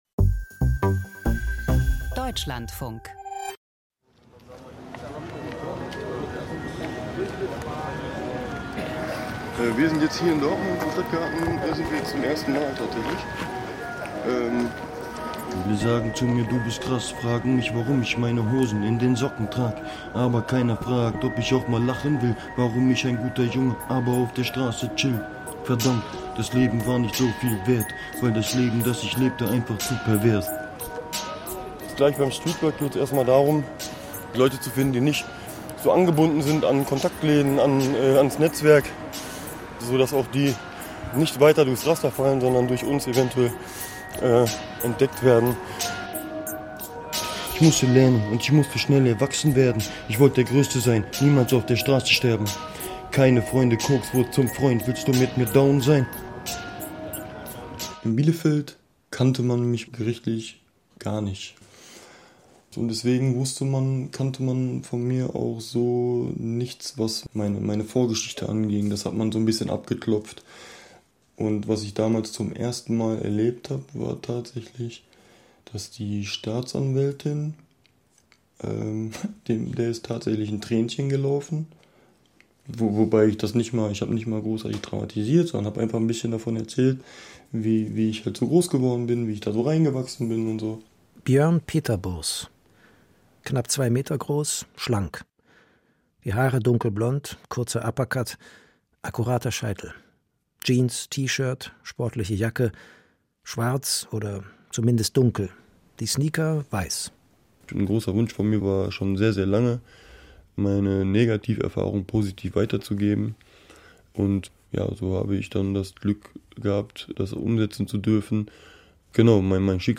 Ein Feature über ein Leben an der Grenze der Gesellschaft.